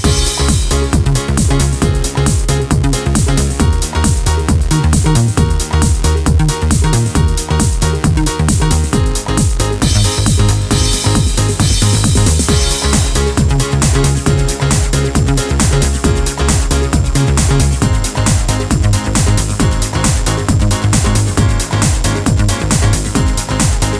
Dance Mix With no Backing Vocals Crooners 3:24 Buy £1.50